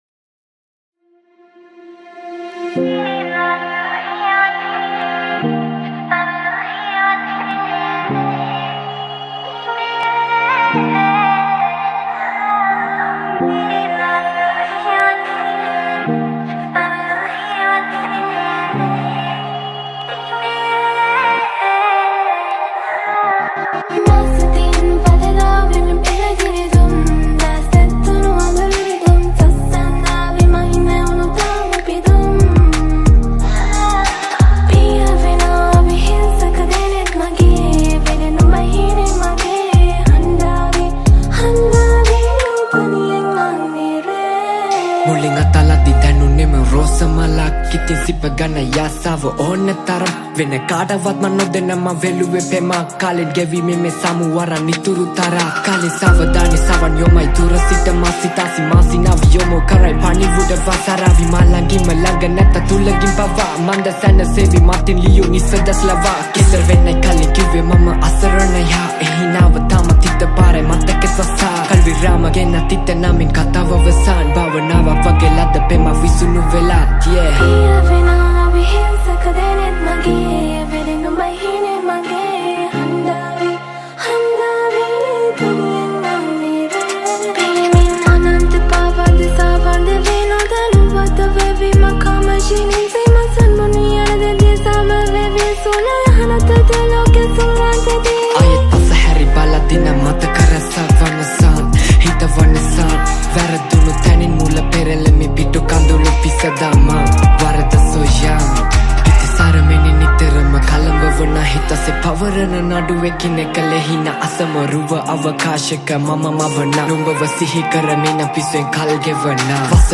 Category: Rap Songs